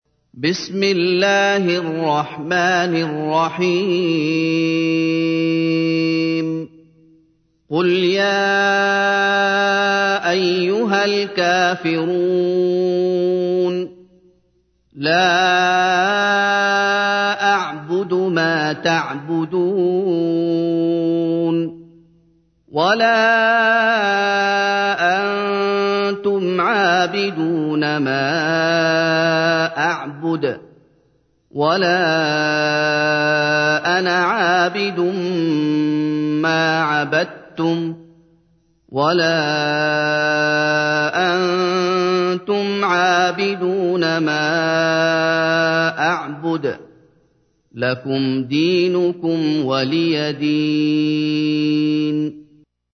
تحميل : 109. سورة الكافرون / القارئ محمد أيوب / القرآن الكريم / موقع يا حسين